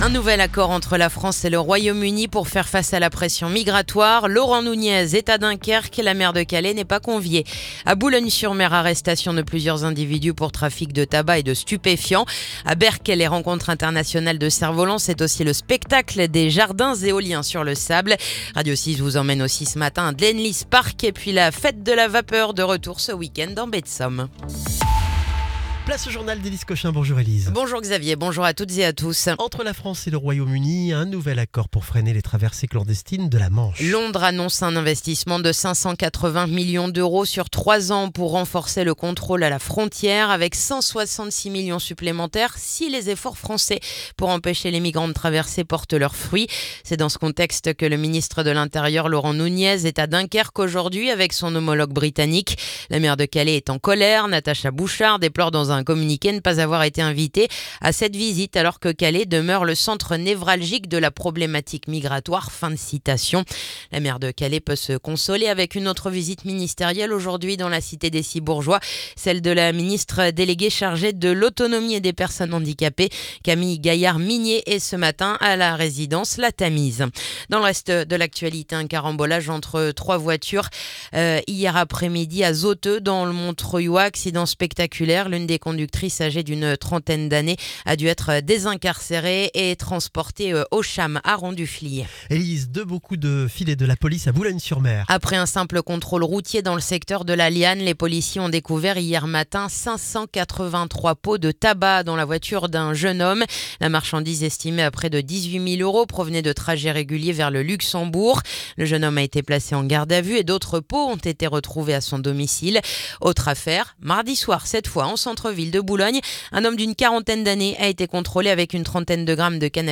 Le journal du jeudi 23 avril